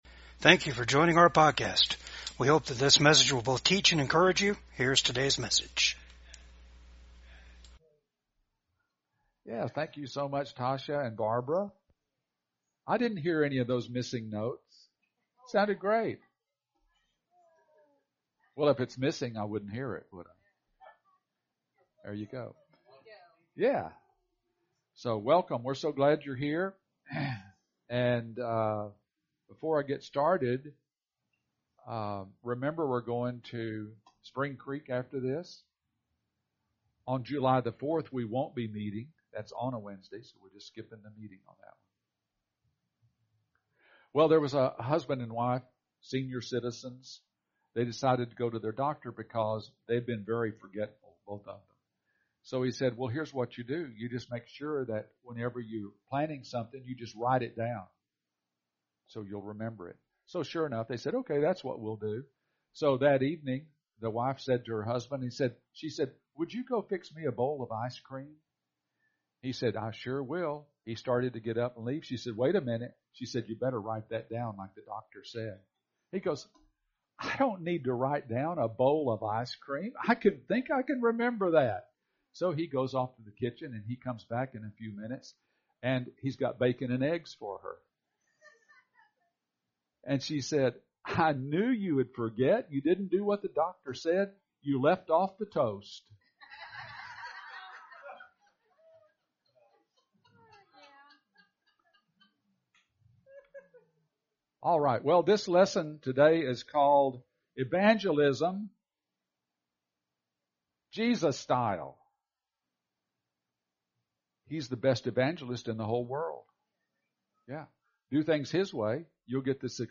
Service Type: VCAG WEDNESDAY SERVICE